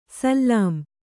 ♪ sallām